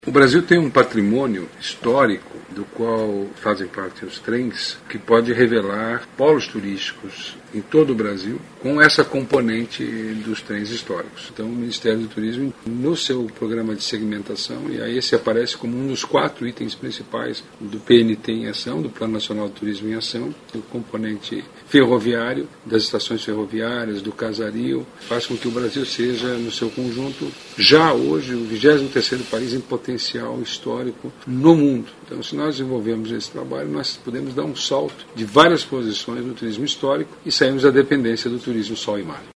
aqui para ouvir comentário do secretário Vinicius Lummertz sobre a importância de investimentos no setor.